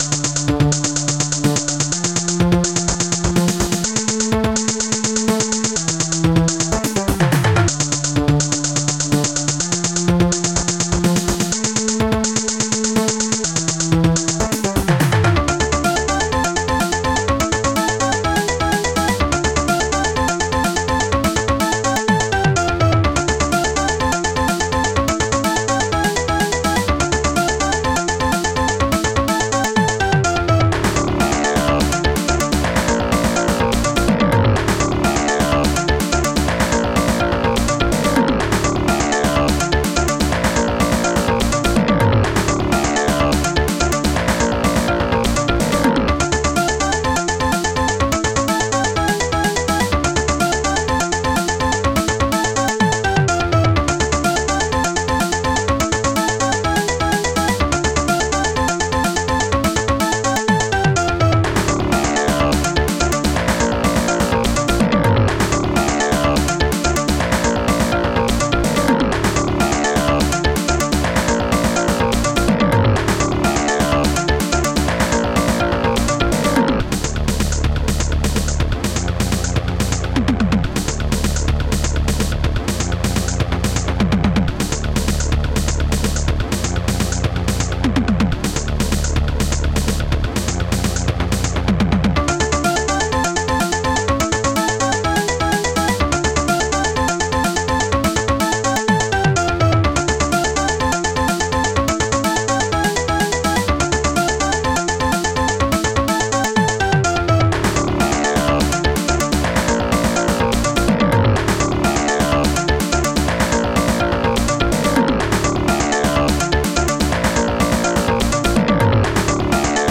bassdrum2 hihat2 popsnare2 korgbass perco dxtom strings6 snare2 woodblock heavysynth claps1 slapbass detune Techbass strings3